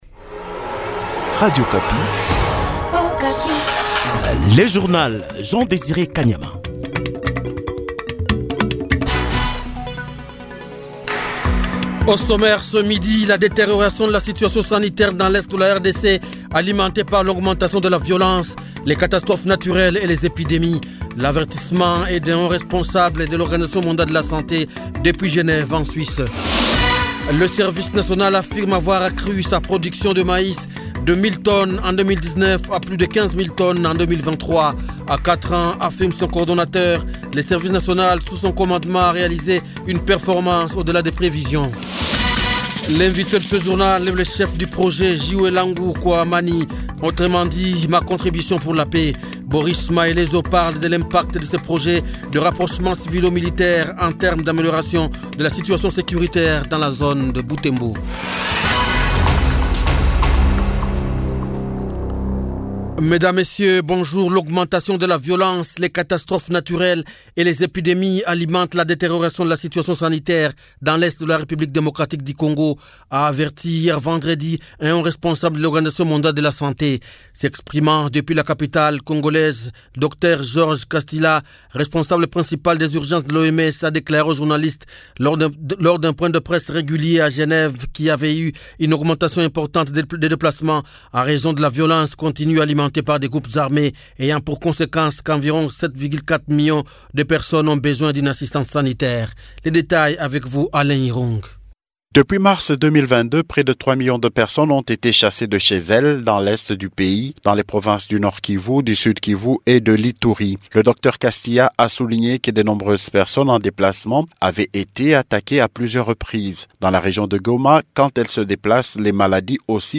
Dans un entretien accordé à Radio Okapi, ce diplomate britannique parle du soutien de son pays, le Royaume Uni, au processus électoral en RDC, en travaillant avec la CENI, le Gouvernement congolais, la société civile et d'autres partenaires. Il évoque aussi la coopération bilatérale entre Kinshasa et Londres.